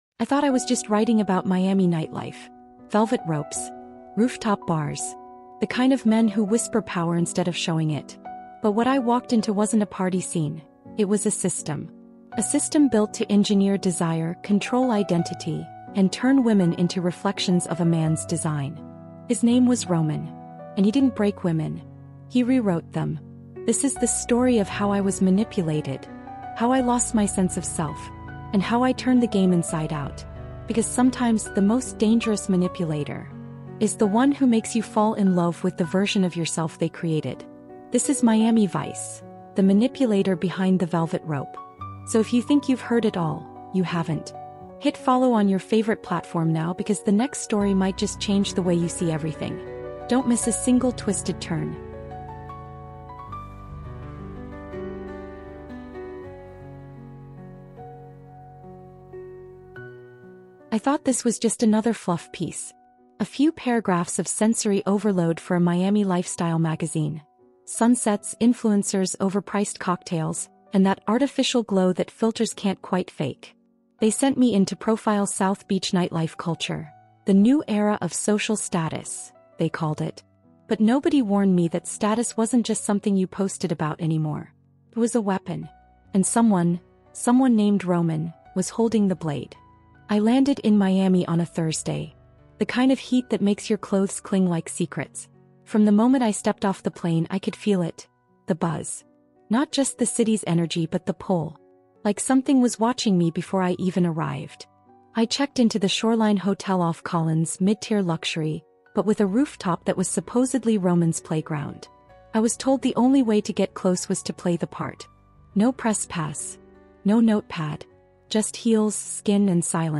Told with emotional realism and cinematic intensity, this story explores how we lose ourselves under the influence of powerful manipulators — and what it takes to rip the velvet rope open.